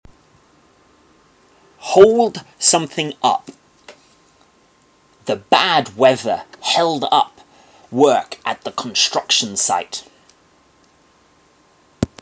自動詞の場合は、主に「 持ちこたえる」「持続する」と言った意味となります。 英語ネイティブによる発音は下記のリンクをクリックしてください。